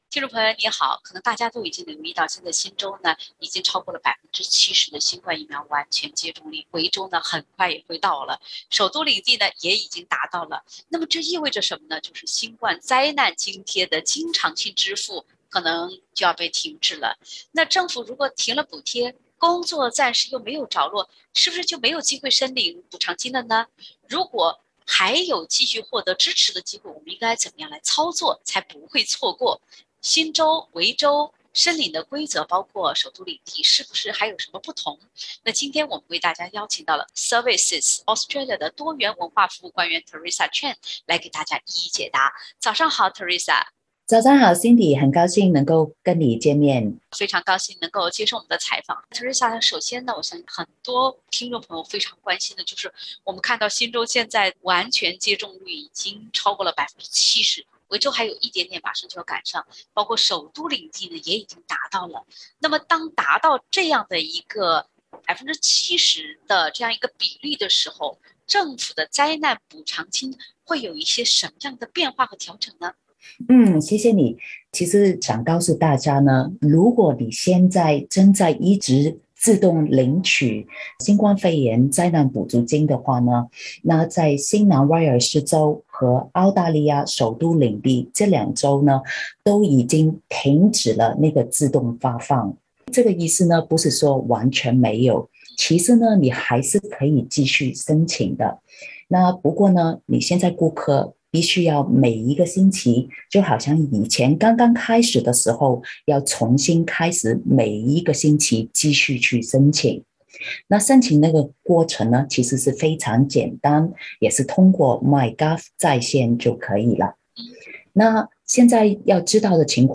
services Australia多元文化服务官员讲解解封后新冠灾难津贴调整。（点击封面图片，收听完整采访）